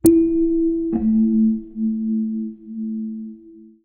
UI_SFX_Pack_61_46.wav